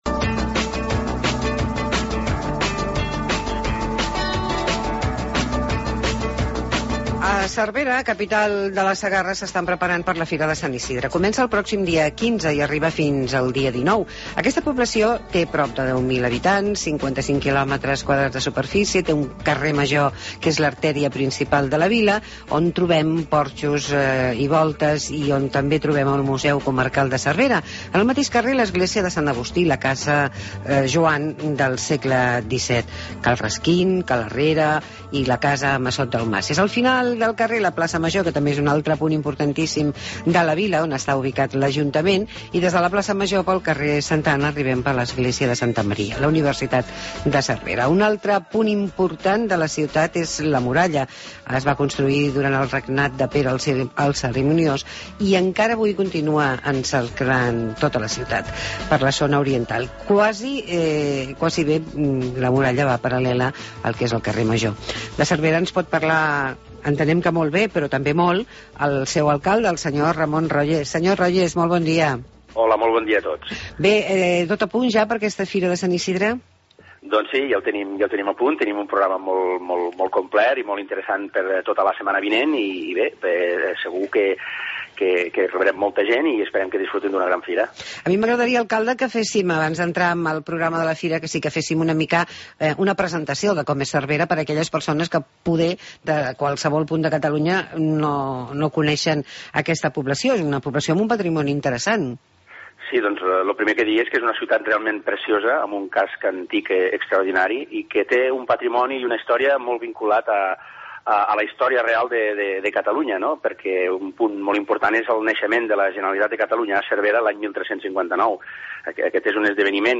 Ramón Royes, alcalde de Cervera ens explica les activitats previstes al municipi